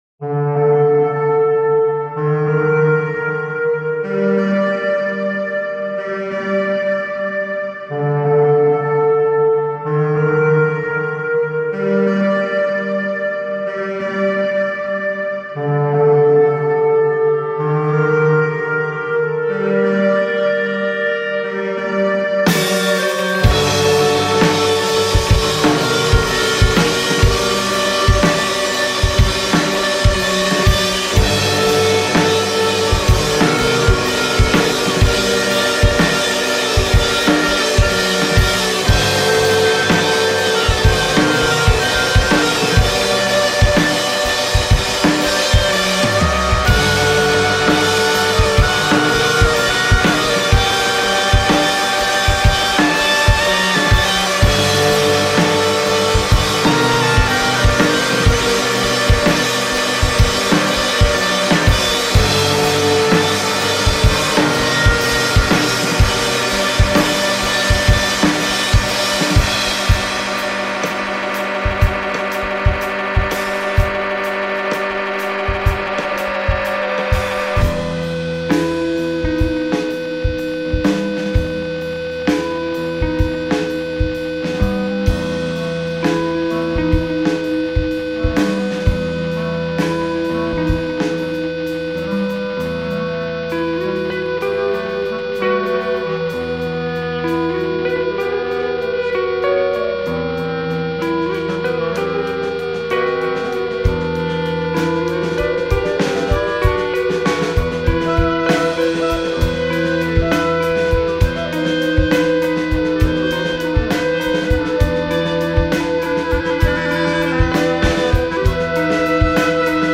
. studio
clarinette
guitare
batterie